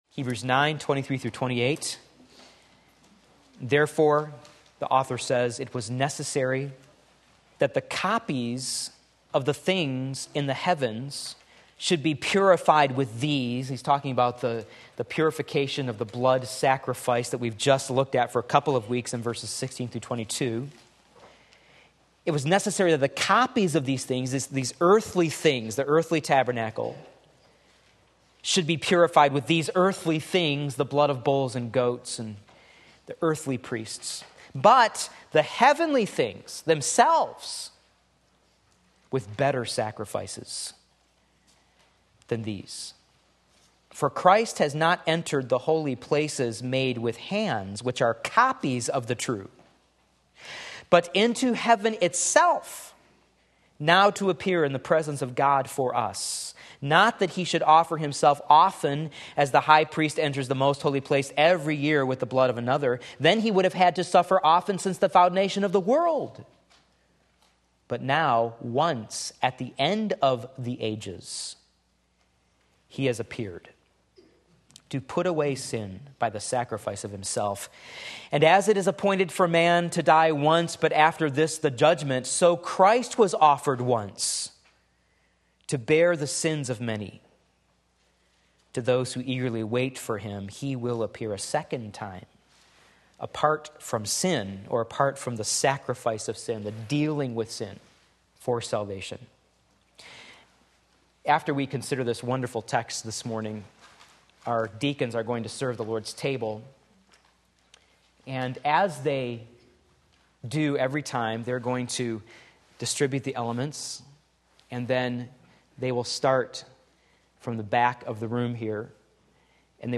Sermon Link
Sunday Morning Service